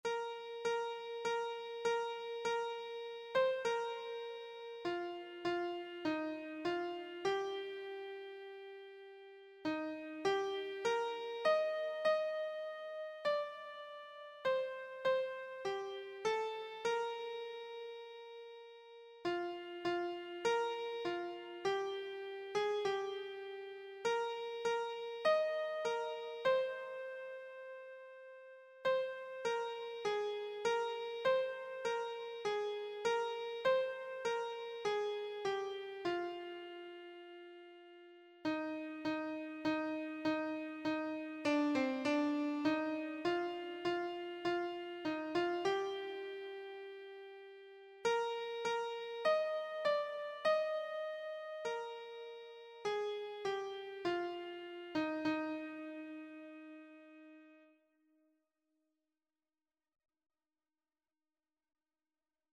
Traditional Sullivan, Arthur Onward, Christian Soldiers Keyboard version
Christian Christian Keyboard Sheet Music Onward, Christian Soldiers
Free Sheet music for Keyboard (Melody and Chords)
Eb major (Sounding Pitch) (View more Eb major Music for Keyboard )
4/4 (View more 4/4 Music)
Keyboard  (View more Easy Keyboard Music)
Traditional (View more Traditional Keyboard Music)